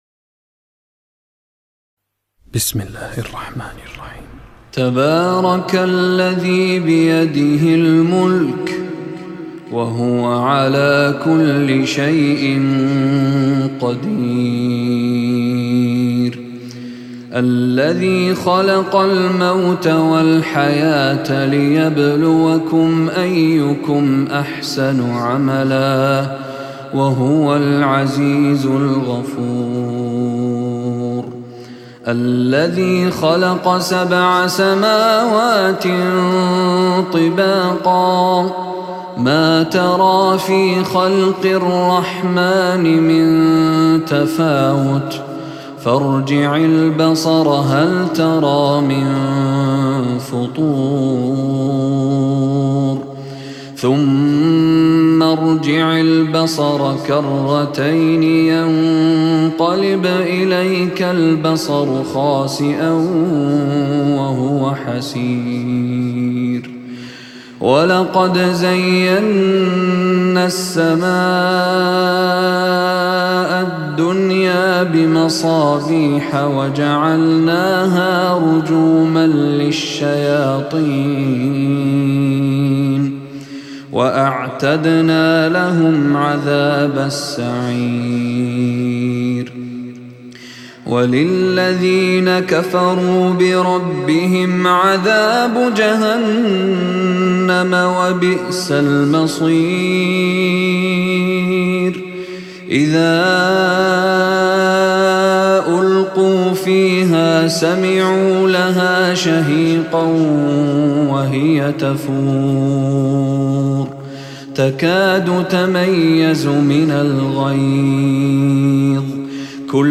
Listening to the recitation of the Quran brings peace to the heart and mind.
سورة-الملك-الشيخ-مشاري-راشد-العفاسي-Surat-Al-Mulk-Mishary-Rashid-Alafasy-Alafasy.mp3